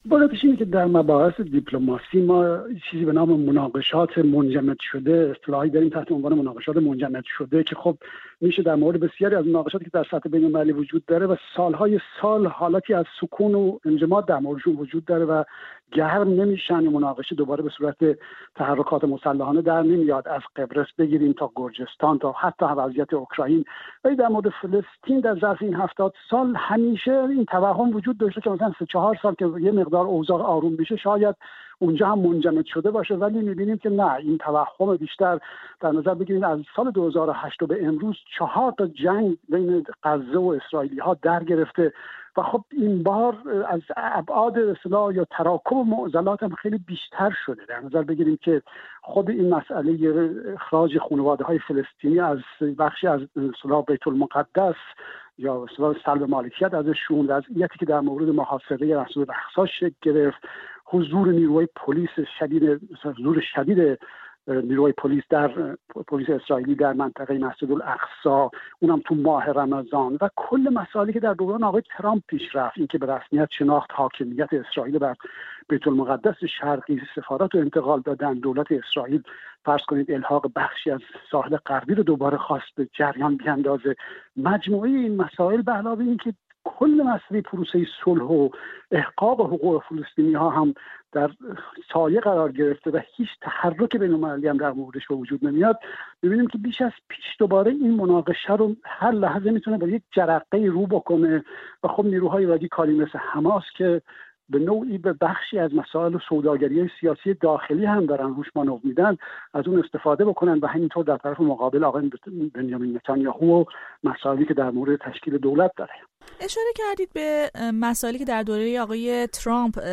درگفتگو